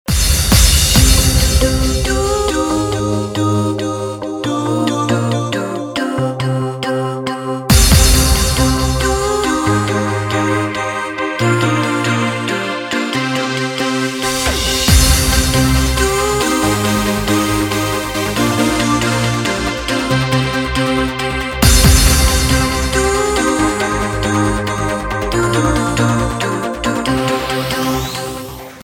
Electronic
красивый женский голос
Trance
Стиль: trance